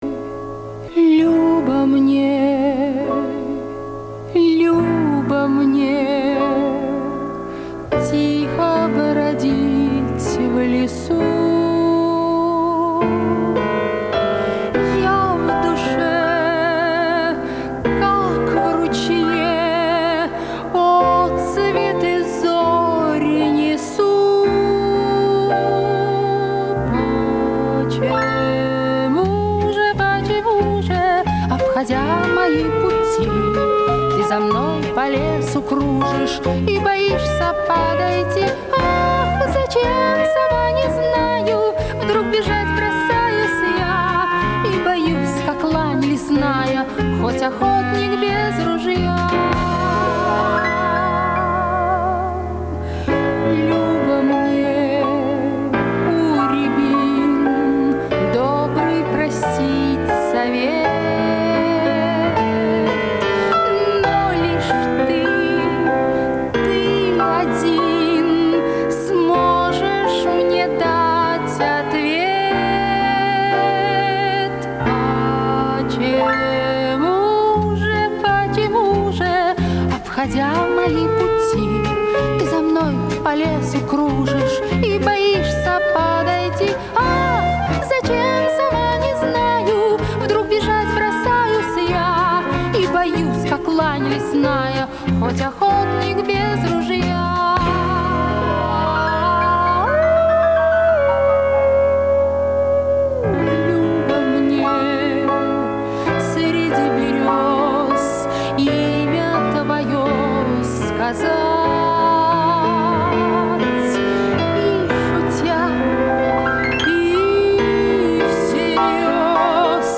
Режим: Mono